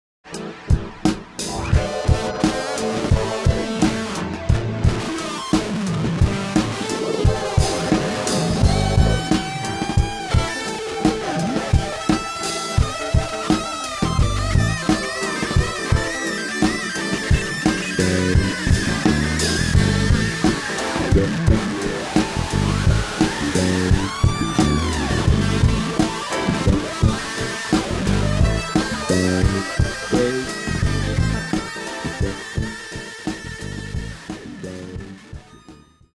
Πετάλι Distortion